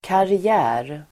Uttal: [kari'ä:r]